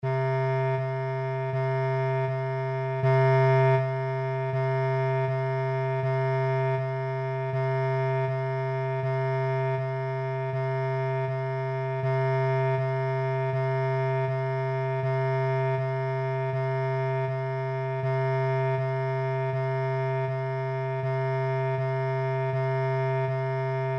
Velaquí atoparedes os arquivos de audio coas notas da escala musical:
Nota DO